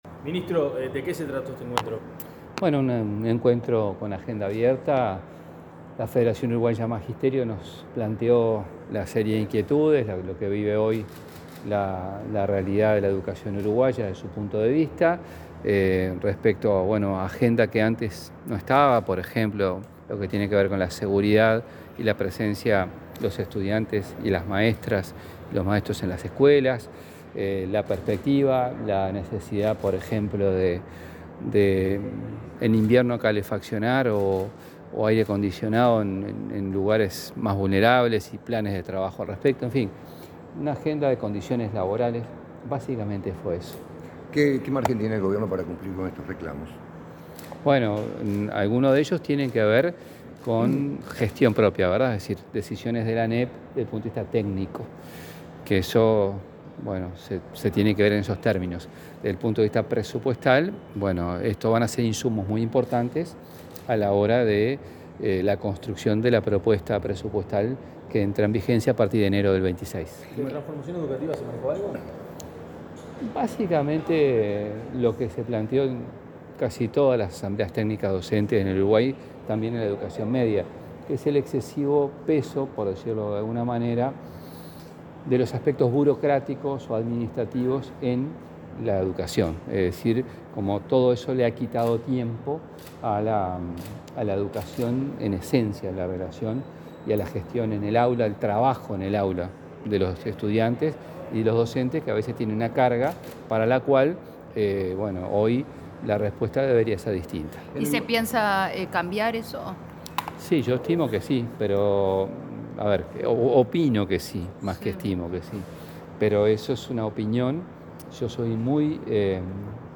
Declaraciones del ministro de Educación, José Carlos Mahía
El ministro de Educación y Cultura, José Carlos Mahía, dialogó con la prensa tras la reunión mantenida con el presidente de la República, profesor